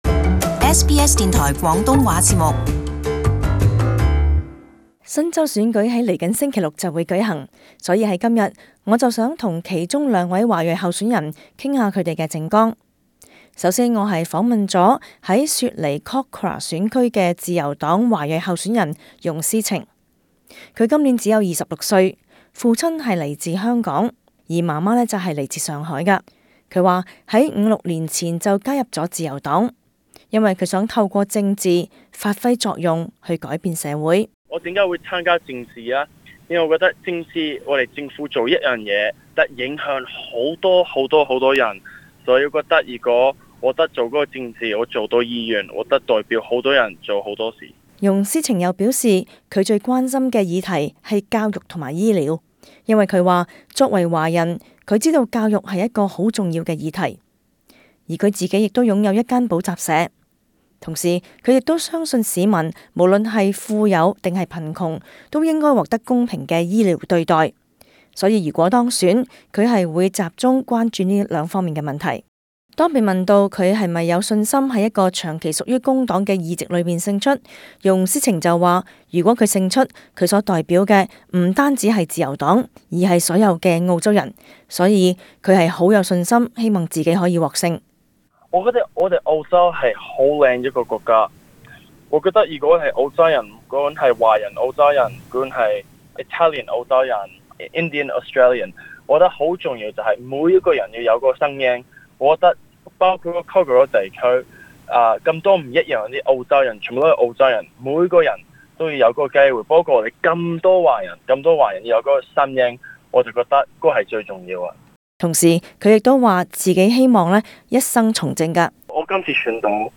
社區專訪